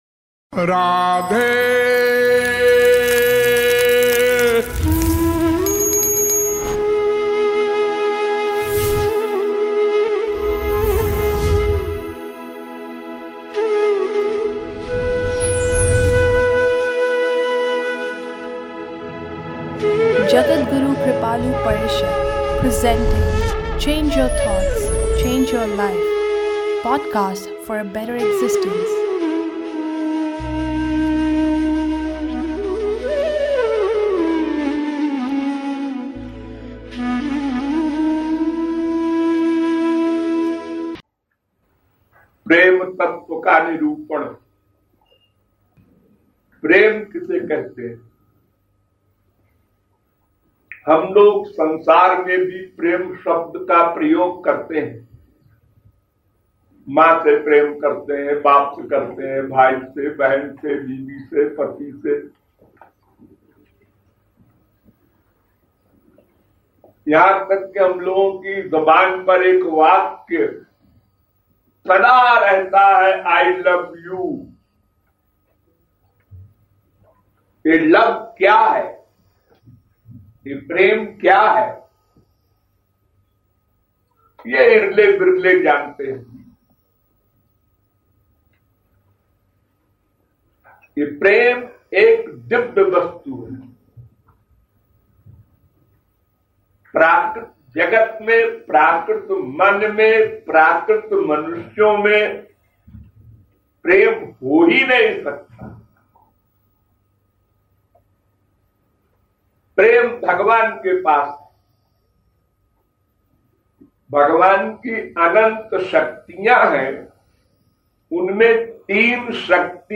In this critical lecture